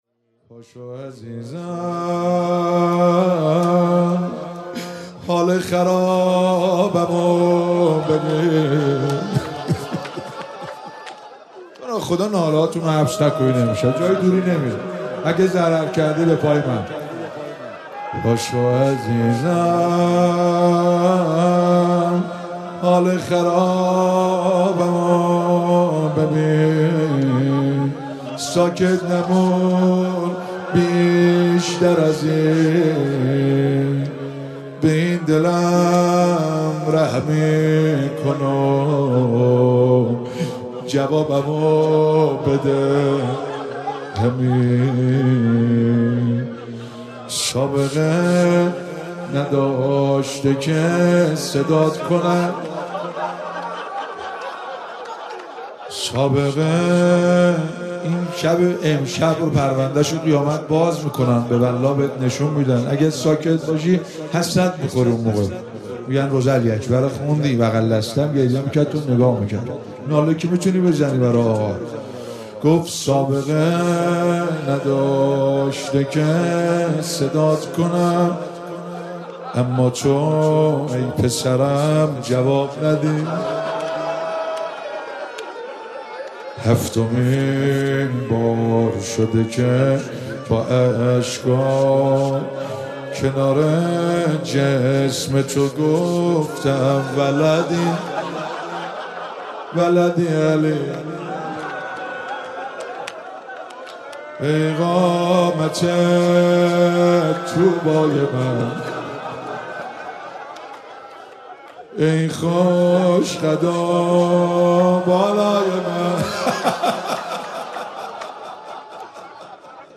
مراسم هفتگی25آذر
روضه - پاشو عزیزم حال خرابمو ببین